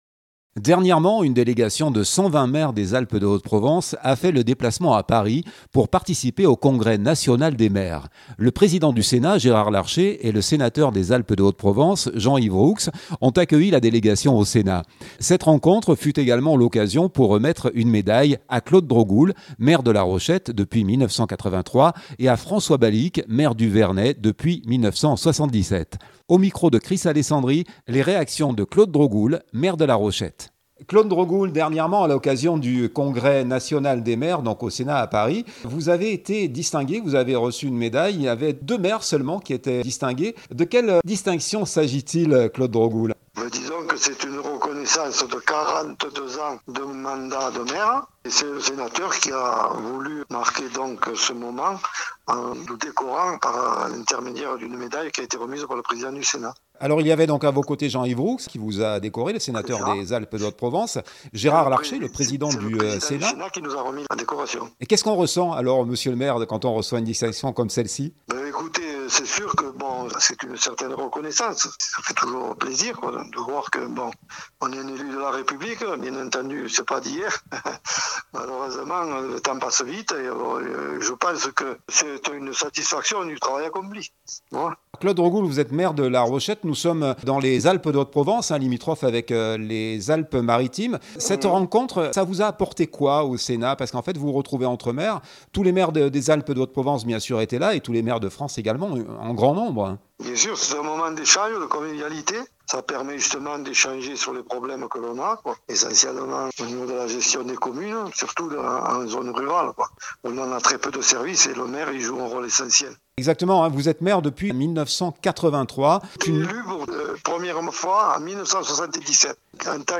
Les réactions de Claude Drogoul maire de La Rochette.